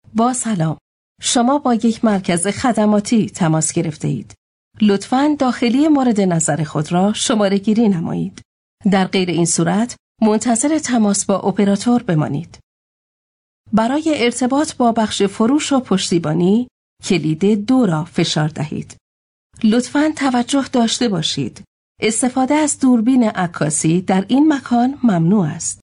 Free voice over demos. Voice overs produced by US and international actors.